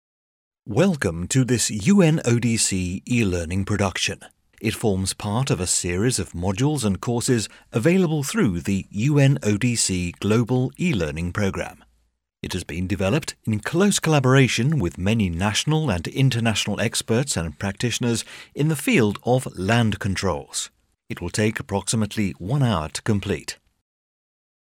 Older Sound (50+)
Warm, articulate British voice with natural authority and clean, confident delivery.
E-Learning